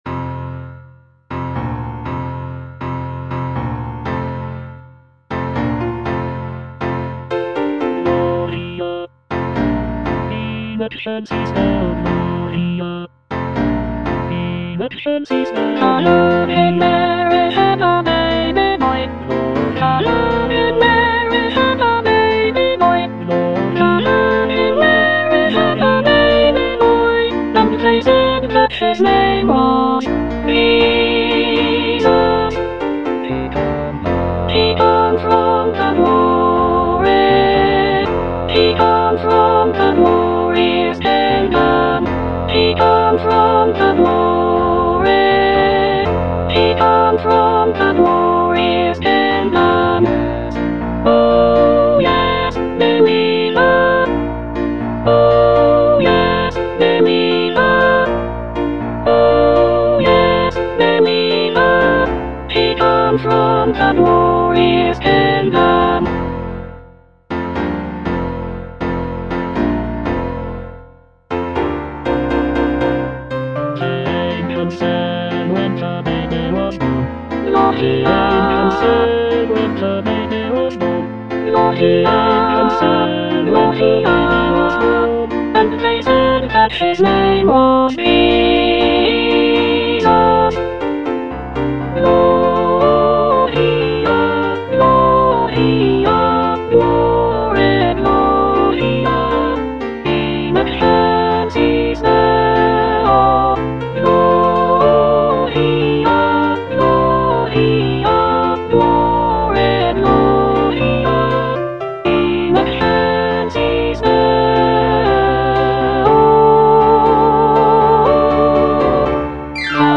Soprano II (Emphasised voice and other voices)
" set to a lively calypso rhythm.